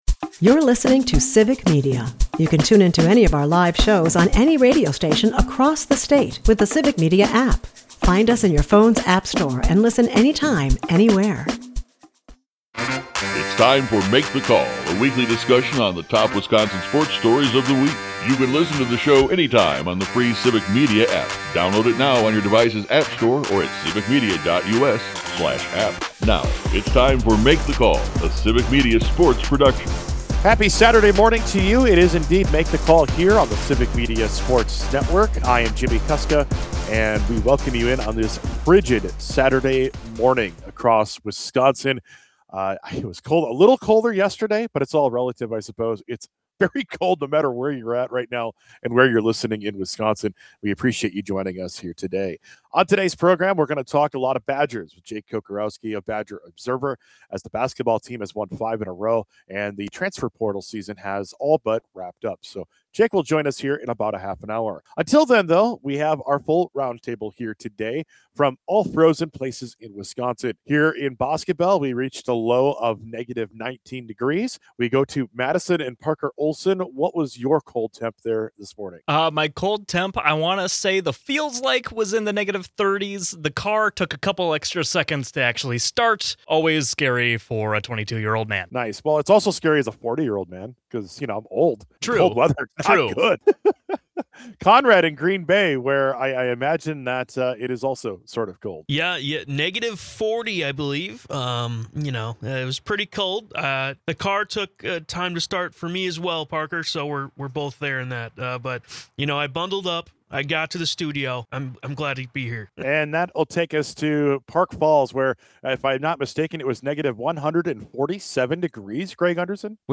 The guys discuss the trade of Freddy Peralta and how the Brewers trade is another piece of the puzzle as talks of an MLB salary cap ramps up. The trade talk continues as they ponder a possible Giannis Antetokounmpo trade.